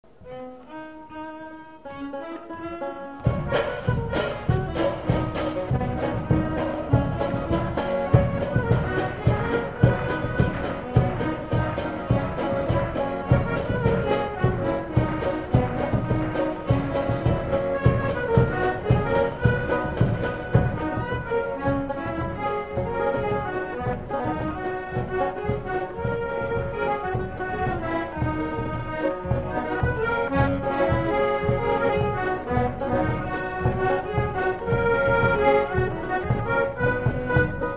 Réunion
Instrumental
danse : séga
Pièce musicale inédite